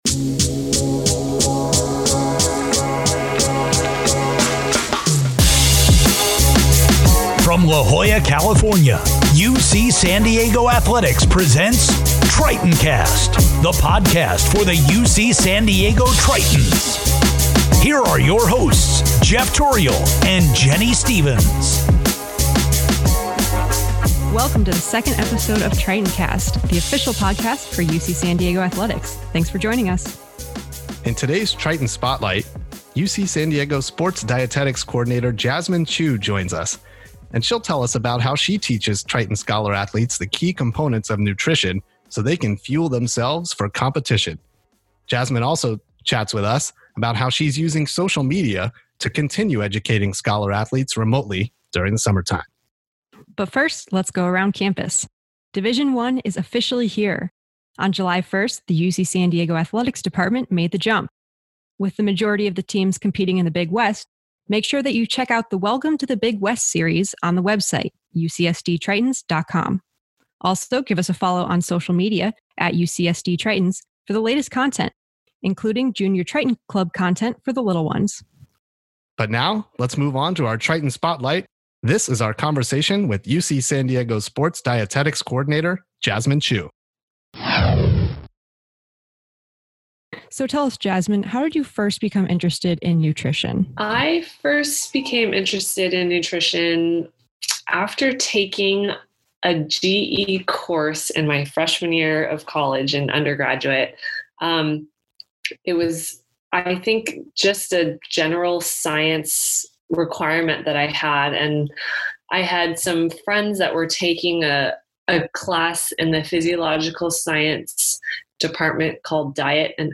Episode two of Tritoncast features a conversation